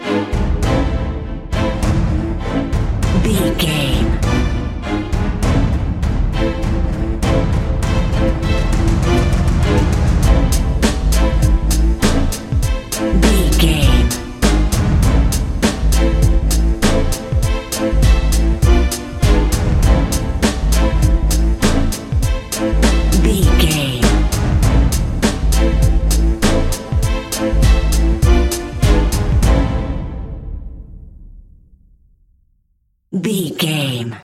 Aeolian/Minor
bass guitar
synthesiser
strings
cello
double bass
drum machine
percussion
hip hop
soul
Funk
neo soul
acid jazz
confident
energetic
bouncy
Triumphant
funky